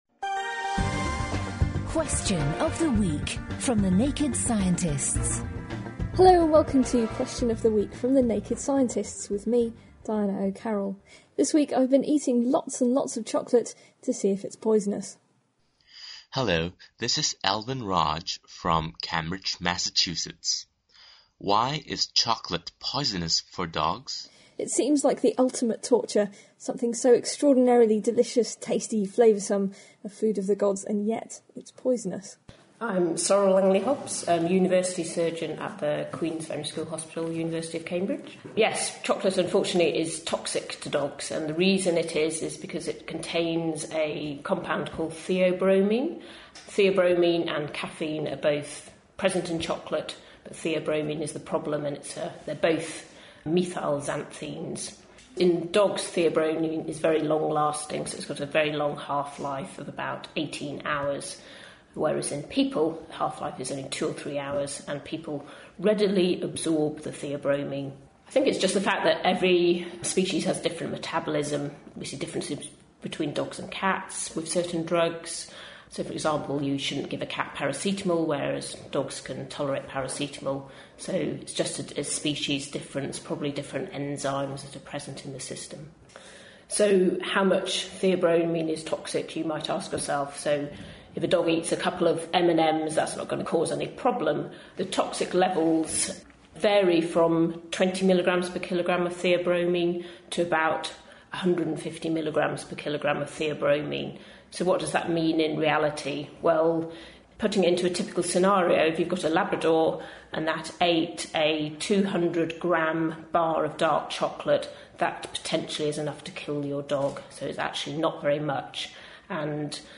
Presented